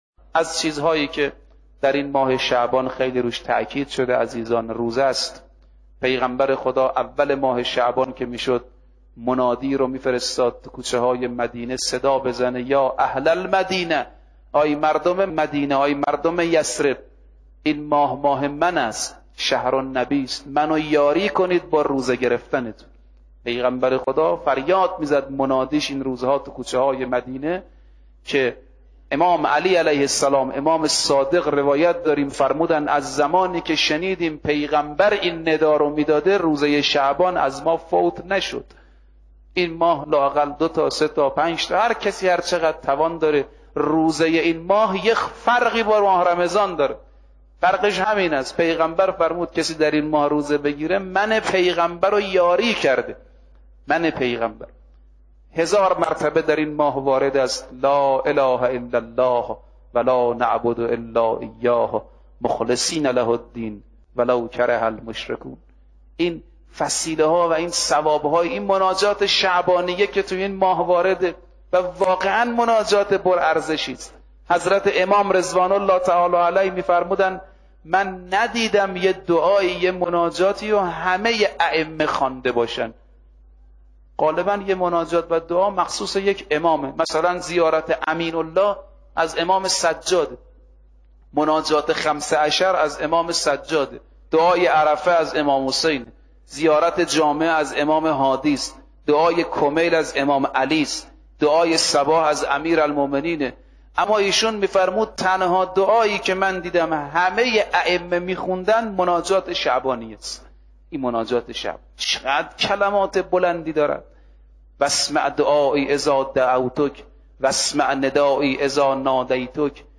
در ادامه بخشی از سخنرانی